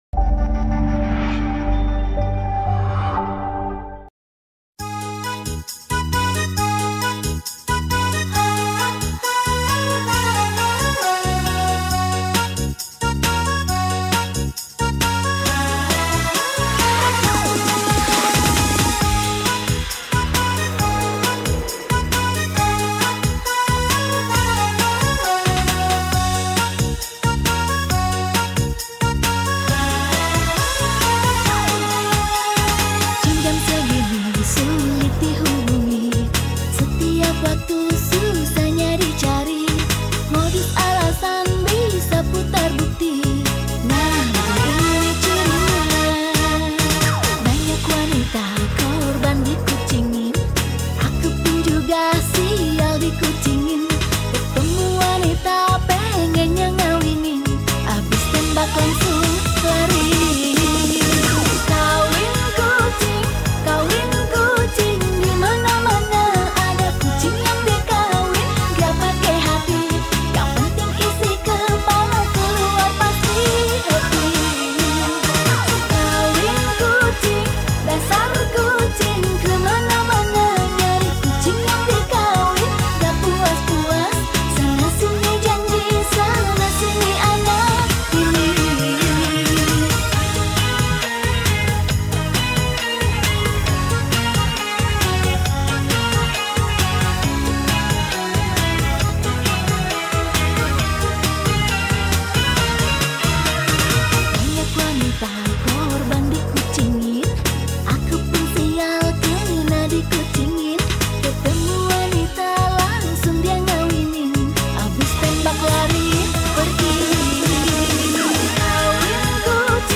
penyanyi dangdut